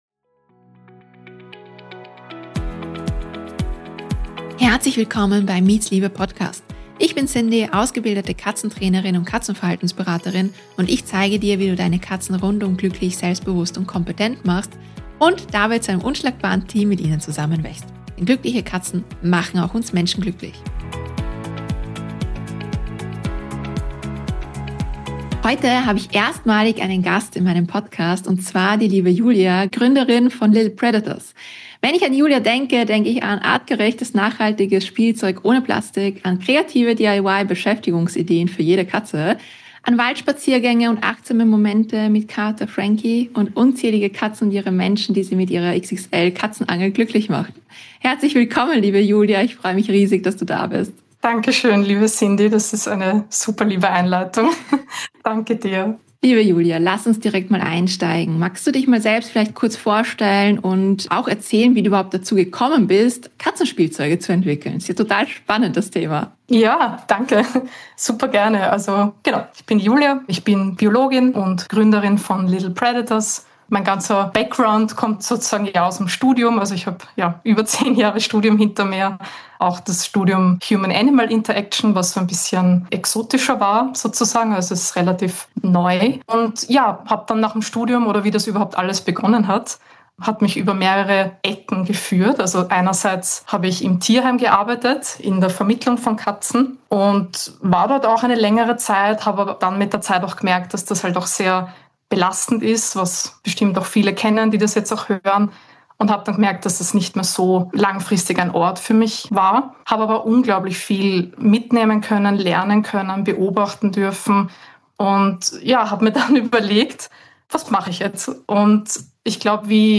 Ein inspirierendes Gespräch über Spiel, Nachhaltigkeit und die tiefe Verbindung zwischen Mensch & Katze